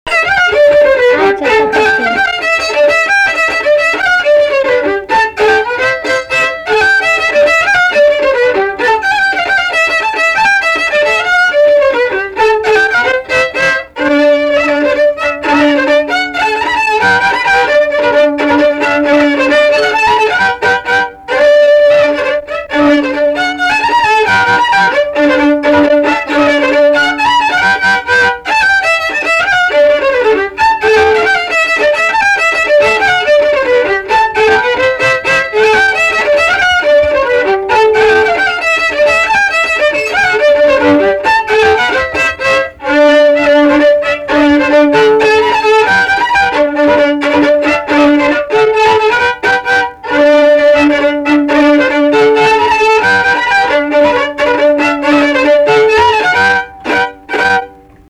Polka
šokis